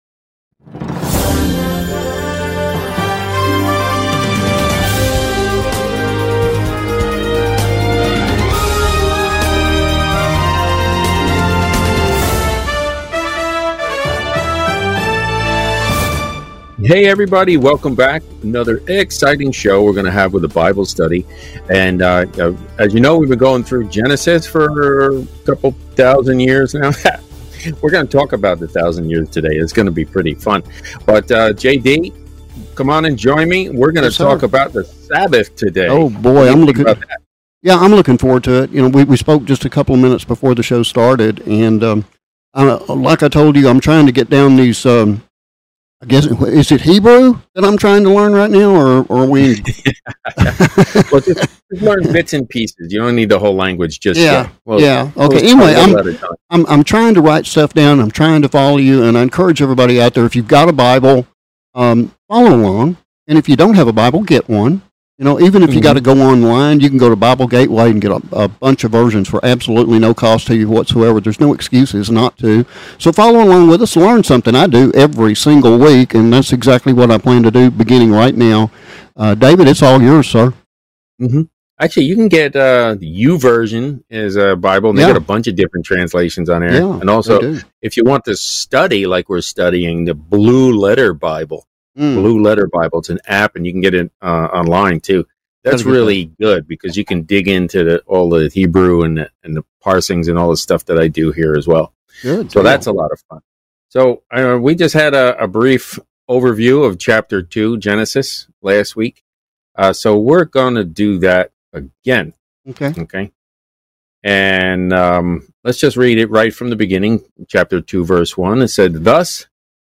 Keeping The Sabbath Holy - Weekly Comprehensive Bible Study